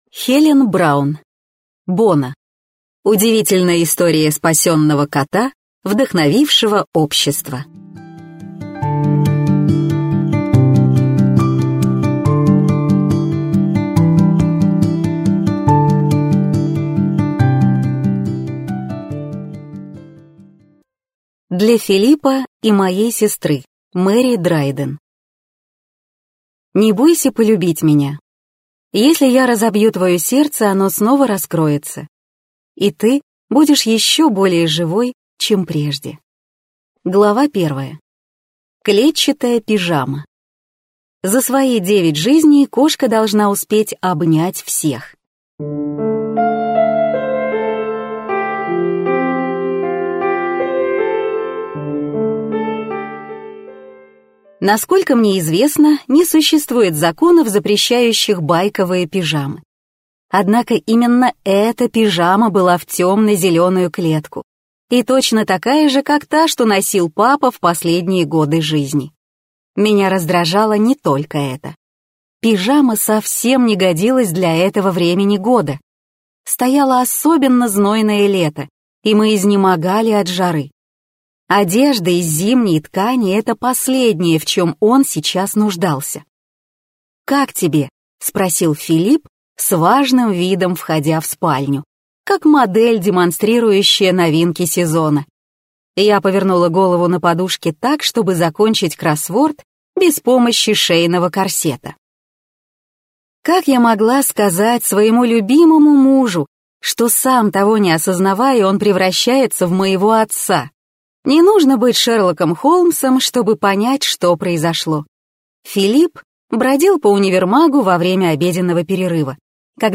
Аудиокнига Боно. Удивительная история спасенного кота, вдохновившего общество | Библиотека аудиокниг